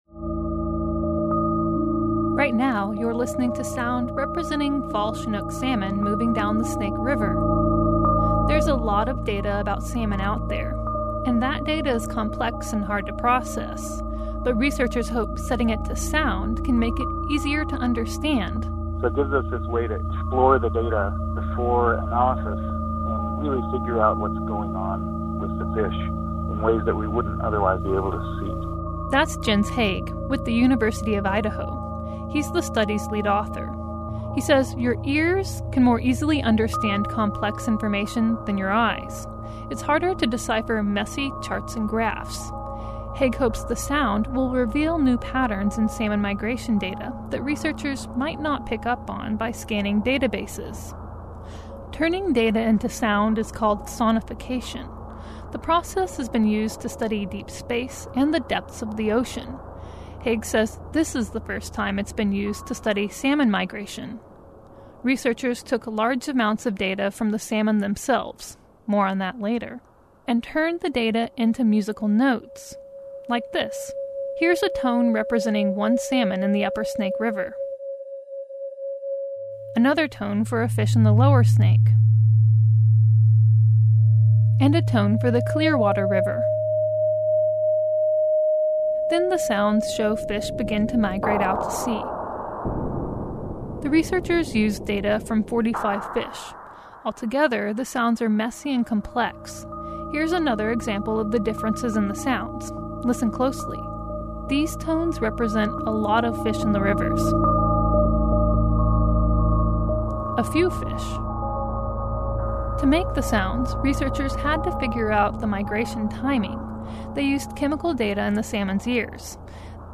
Each fish was assigned a tone. A bell rings to represent each salmon moving downstream.
Layered together, the sound becomes messy as more and more fish get on the move. Toward the end of the track, the tones calm down.